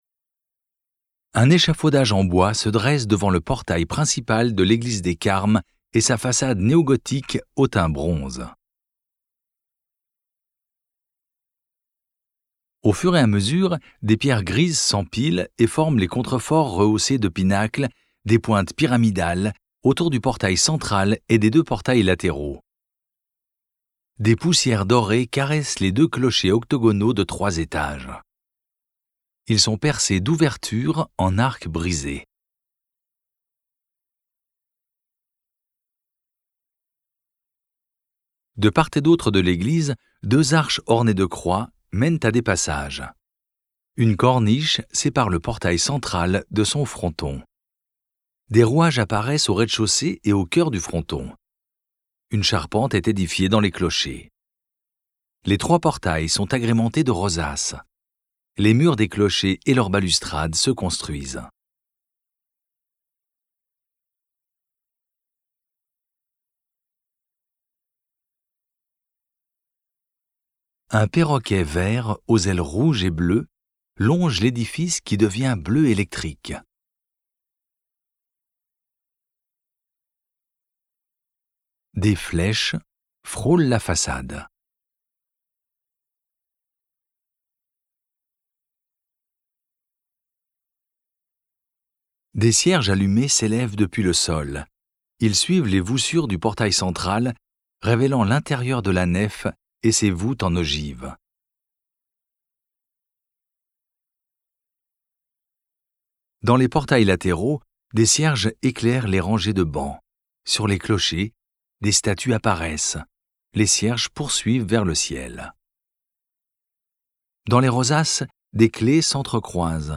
FRDL - audiodescription Le Puy-en-Velay.mp3